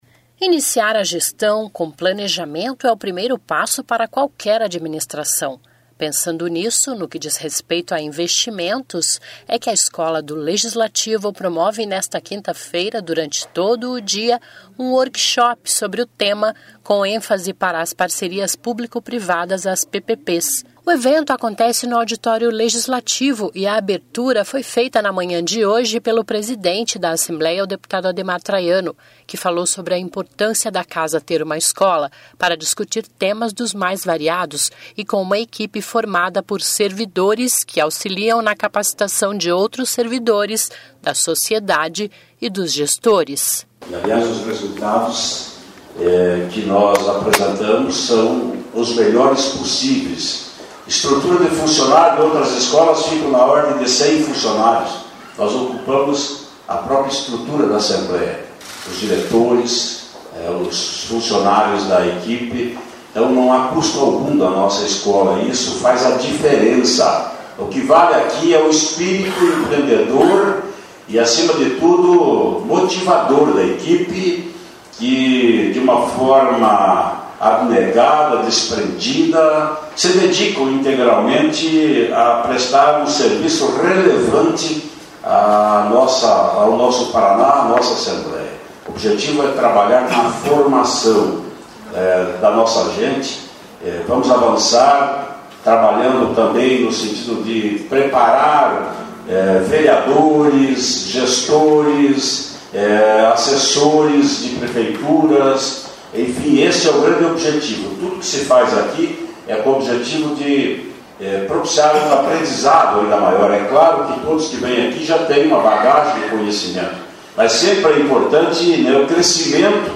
(Sobe som)
(Sonora)